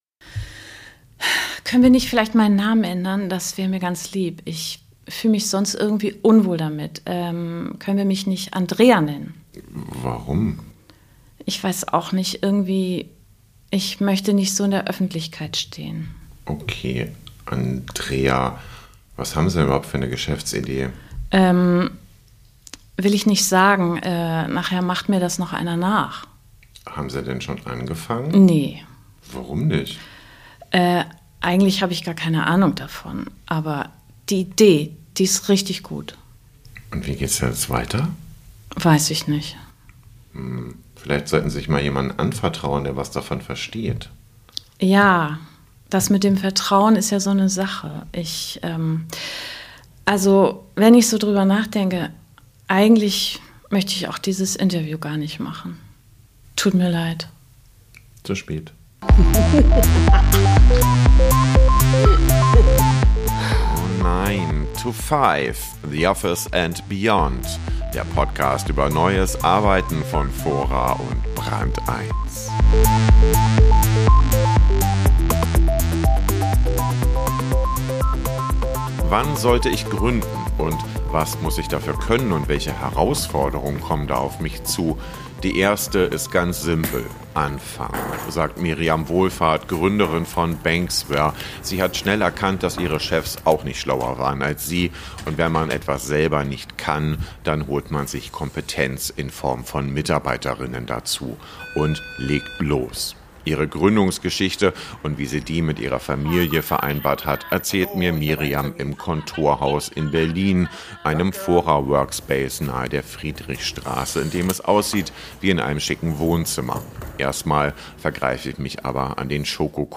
Ein Gespräch über böse Briefe von der BaFin, das Führungszeugnis von Michael Otto, die deutsche Tugend des langen Büro-Sitzens, das Reizthema Gendern und Chefs, die sich nicht mehr gebraucht fühlen. Das Interview fand im Fora-Kontorhaus in Berlin statt.